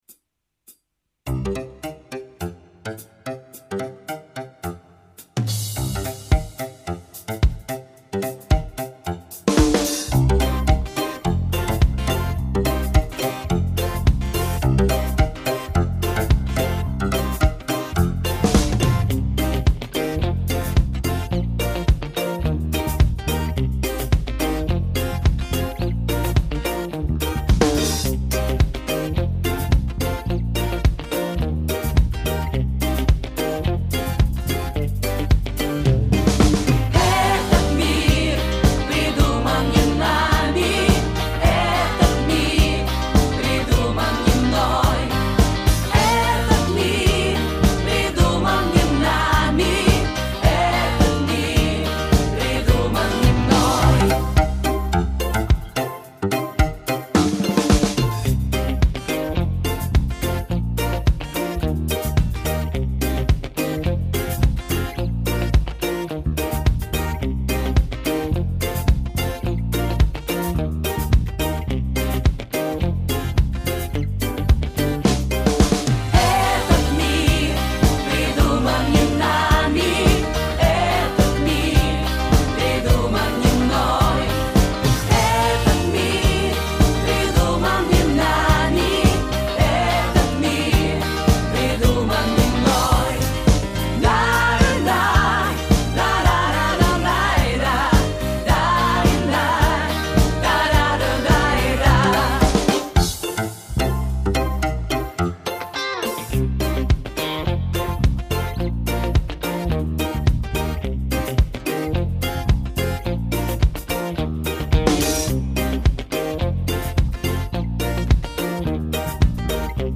Это, насколько я разбираюсь, минус с бэком